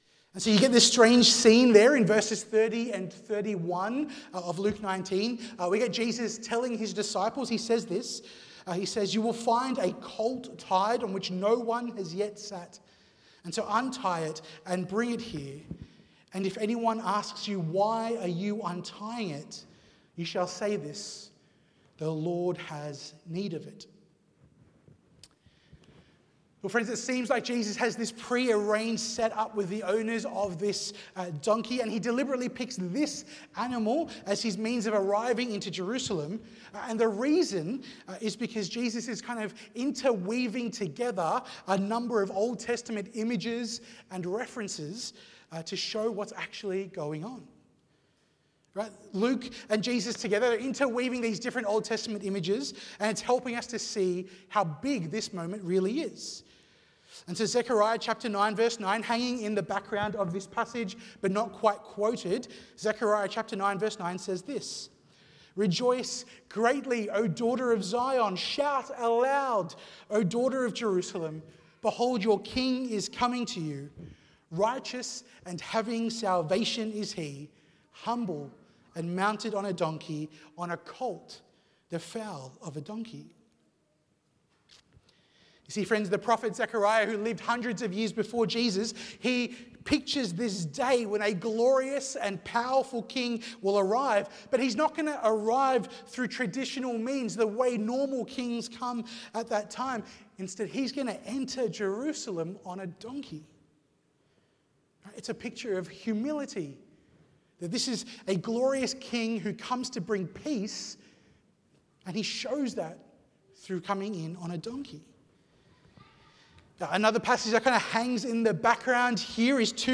Due to a technical issue, the first part of the sermon audio was not captured. Sermon audio starts part way through